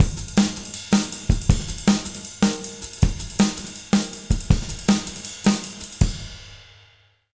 160JUNGLE4-L.wav